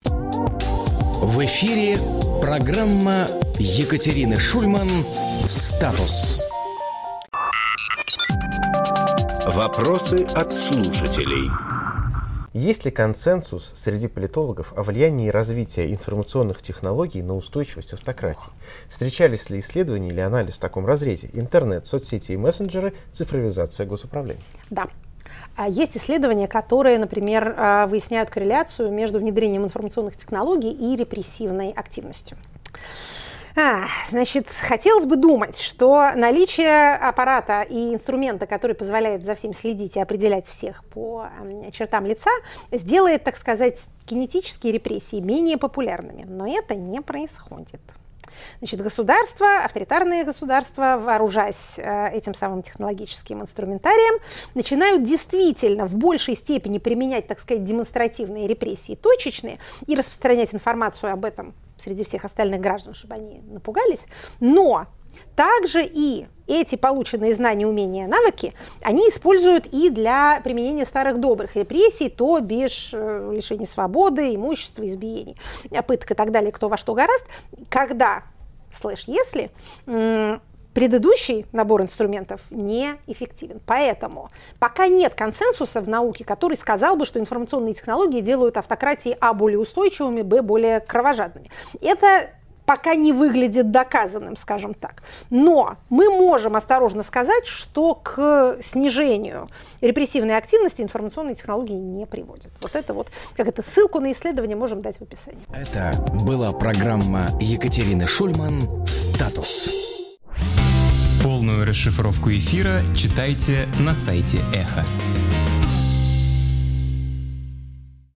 Екатерина Шульманполитолог
Фрагмент эфира от 7 января.